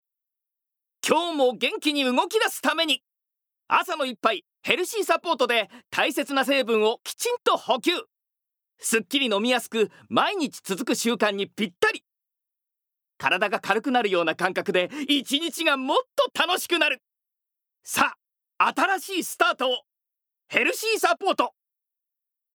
Voice Sample
ナレーション２